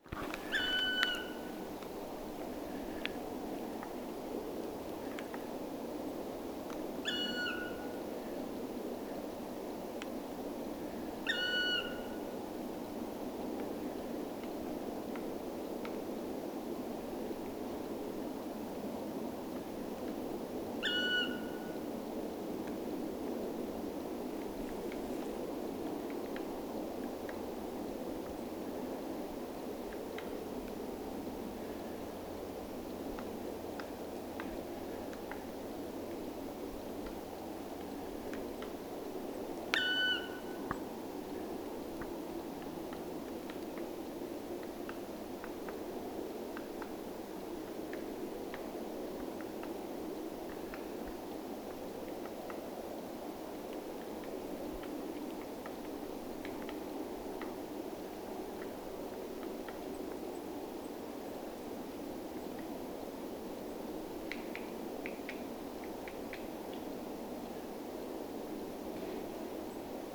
palokärjen ääniä
Tuollaista on usein palokärjen työskentelyäänet,
Nokkaisut ovat kuin harkittuja ja harvakseltaan.
palokarki_nokkaisuja_nokkaisuaania.mp3